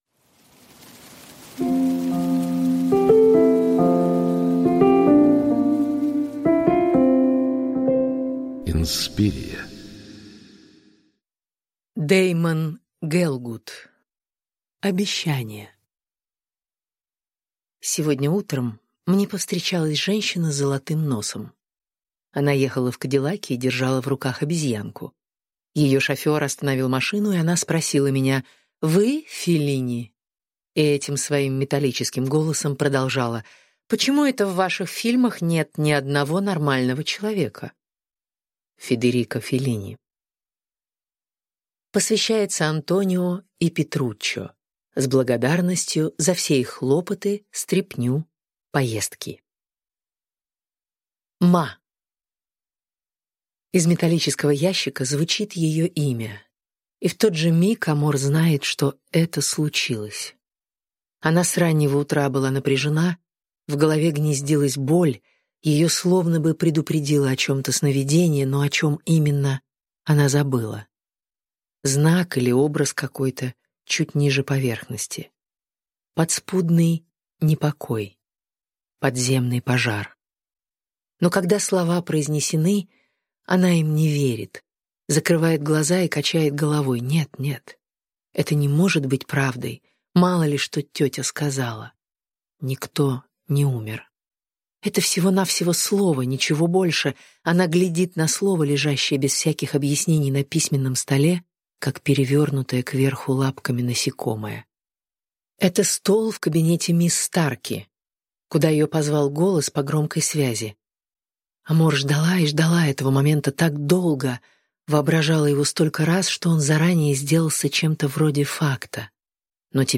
Аудиокнига Обещание | Библиотека аудиокниг